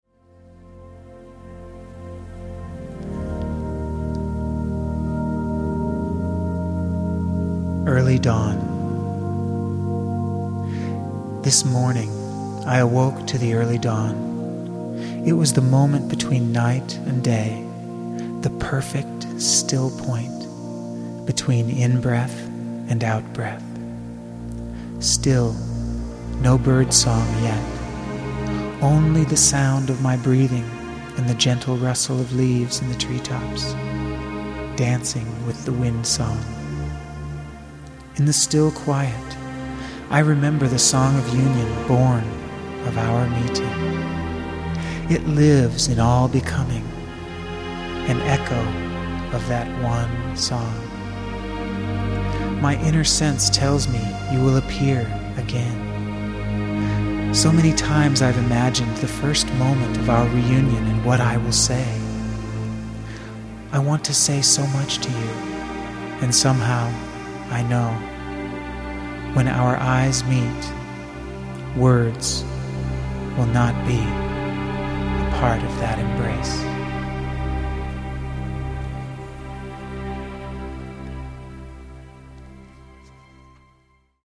Ecstatic Poetry & Music for the Lover in All...